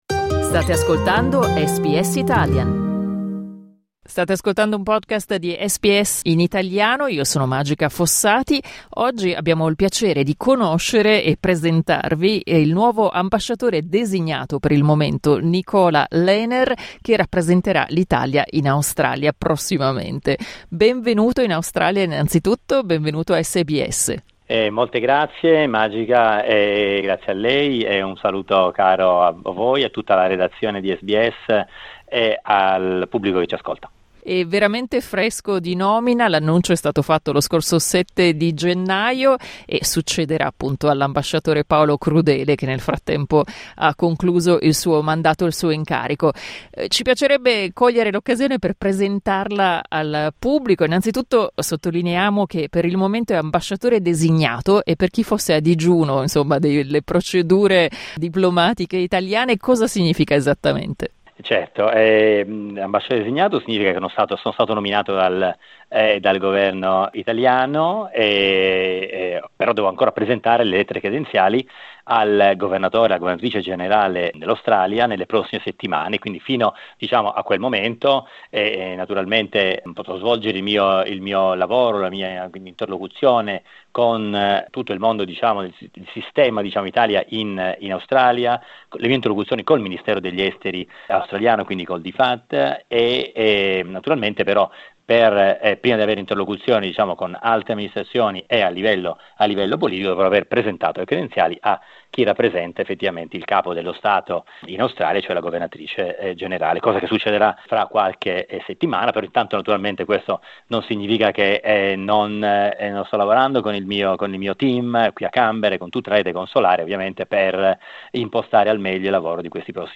Un asse fondamentale [della nostra attività] sarà ovviamente costituito dalla presenza dei nostri connazionali, e tanti australiani di origine italiana Nicola Lener Al momento Lener si trova già a Canberra, dove l'abbiamo raggiunto telefonicamente per conoscerlo e farvelo conoscere.
Clicca sul tasto "play" in alto per ascoltare l'intervista integrale Ascolta SBS Italian tutti i giorni, dalle 8am alle 10am.